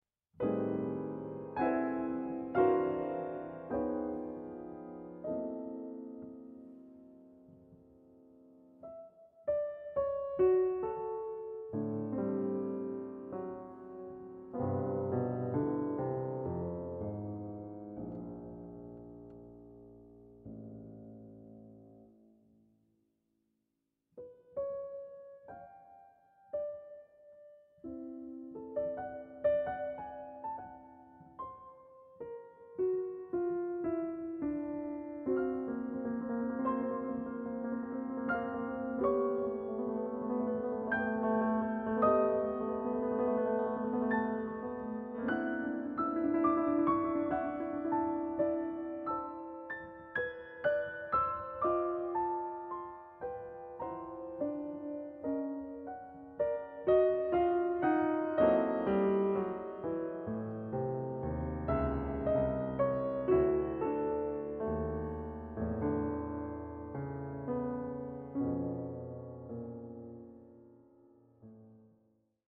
• Genres: Solo Piano, Classical
Recorded at LeFrak Concert Hall, Queens College, CUNY, 2011